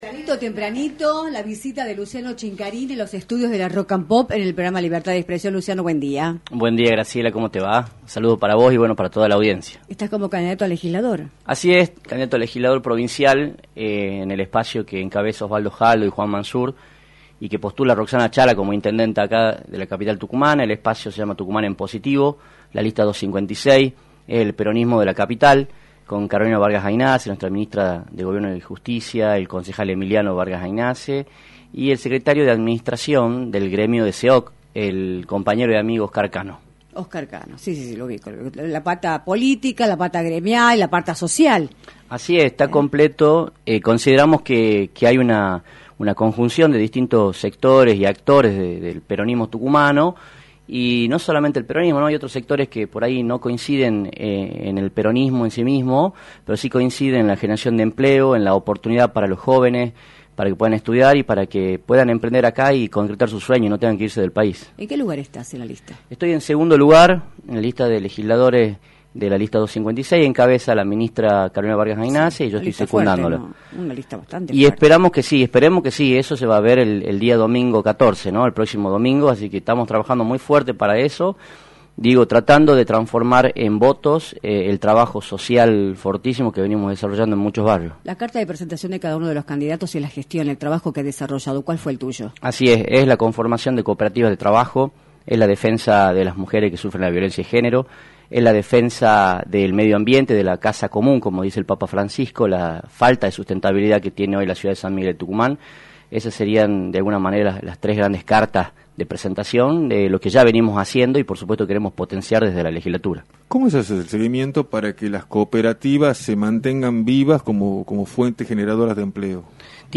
visitó los estudios de “Libertad de Expresión”, por la 106.9, para analizar el panorama político y electoral de la provincia
entrevista